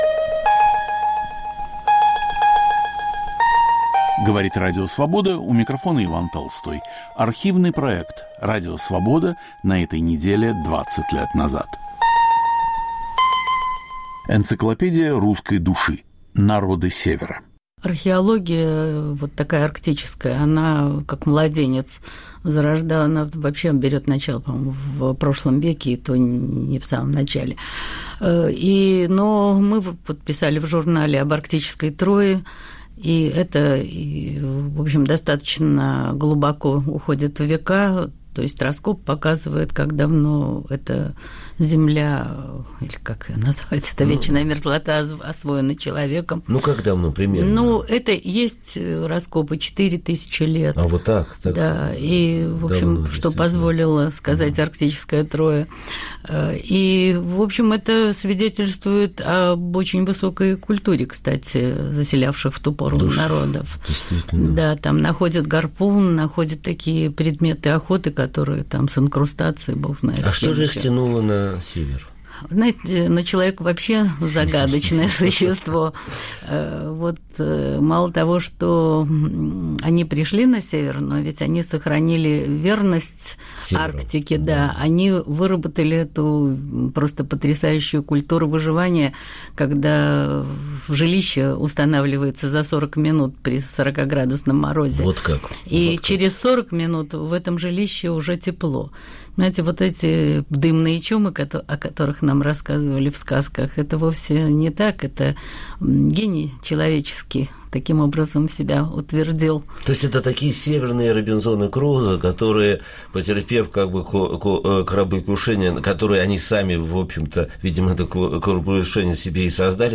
Автор и ведущий Виктор Ерофеев.